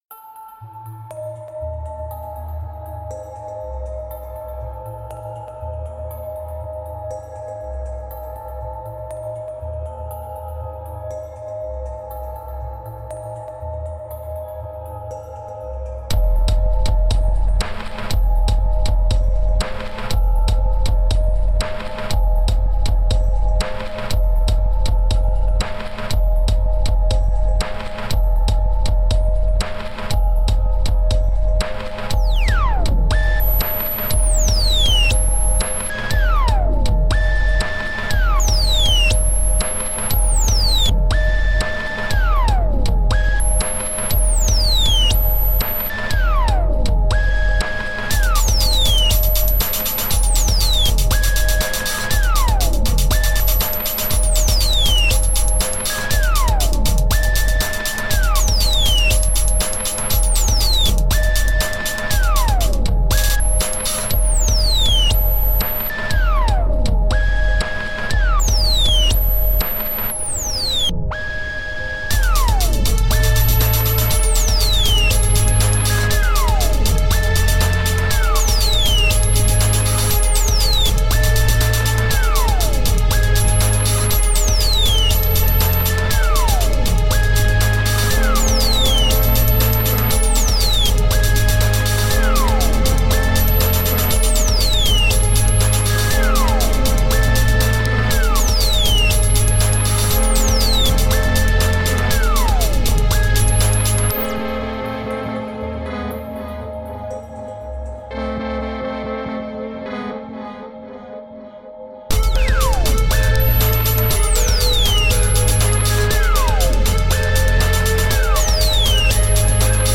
Dark ambient with an alchemical twist.
Tagged as: Electronica, Other